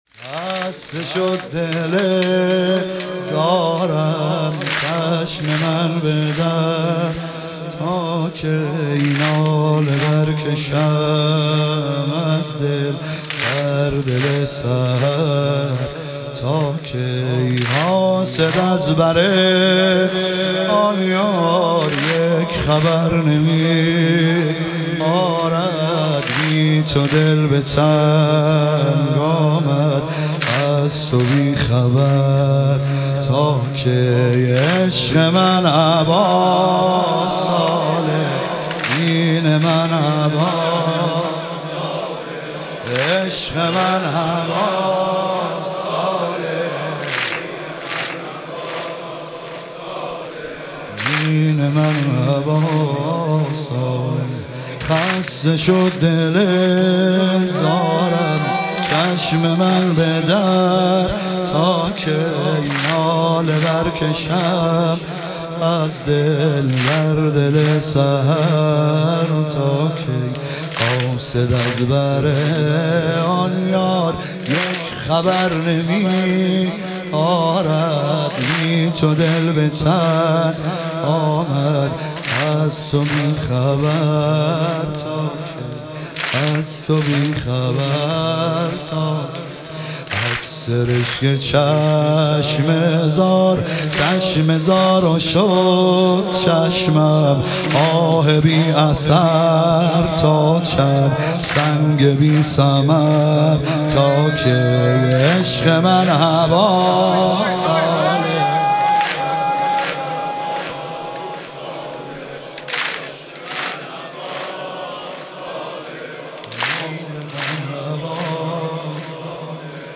شب شهادت امام صادق علیه السلام